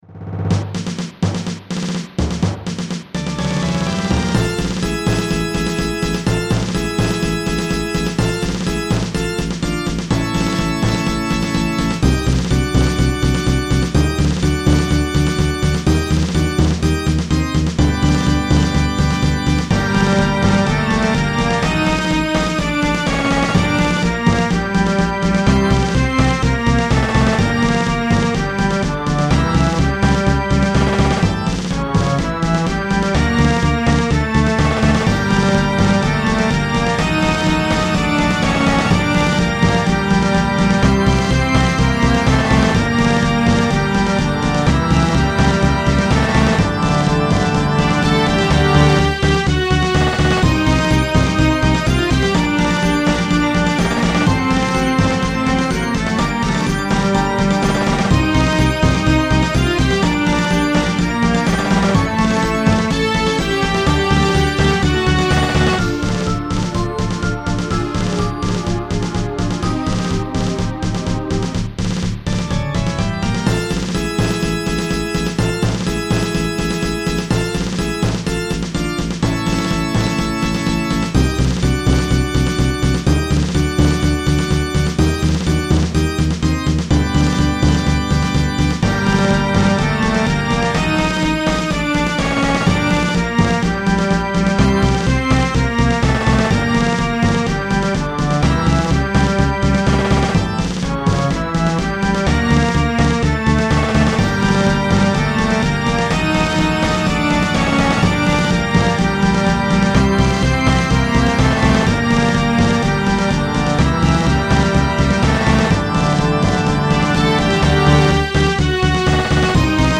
Music / Game Music
Sound more like N64 than SNES imo